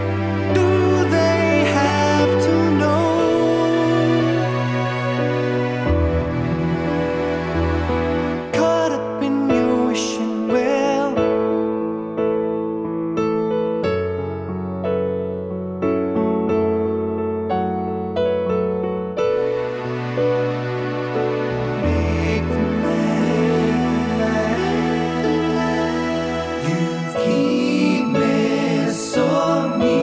No Guitar And no Backing Vocals Pop (1990s) 3:44 Buy £1.50